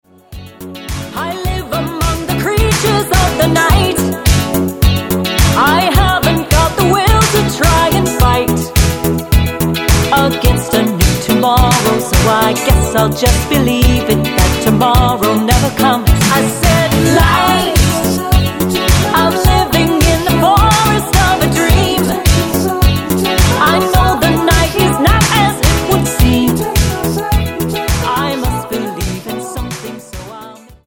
Tonart:F#m Multifile (kein Sofortdownload.